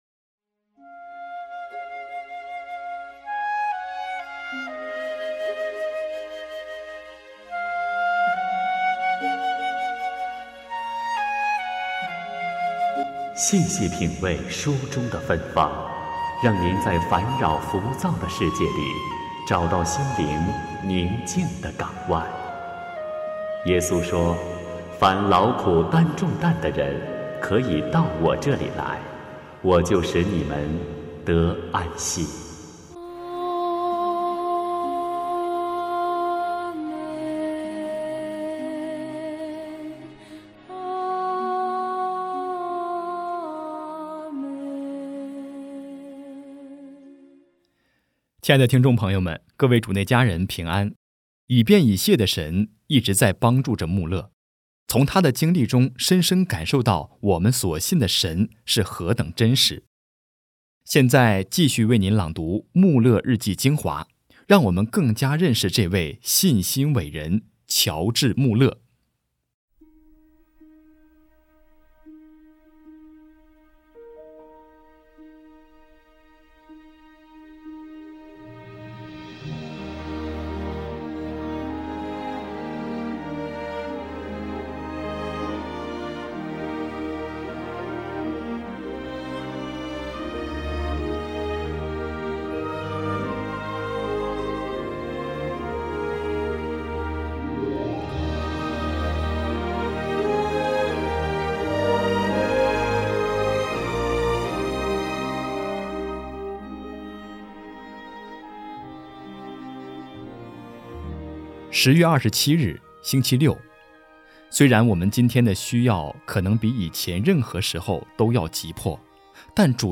现在继续为您朗读 《慕勒日记精华》， 让我们更加认识这位信心伟人—-乔治·慕勒。